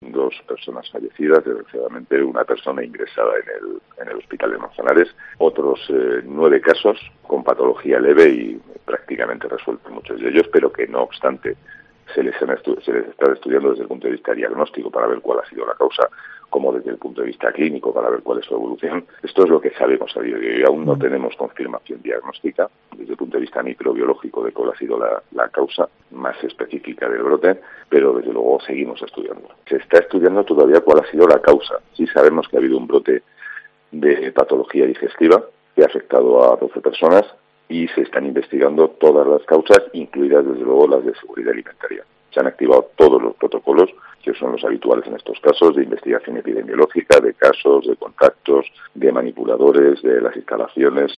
Juan Camacho, director general de Salud Pública de Castilla-La Mancha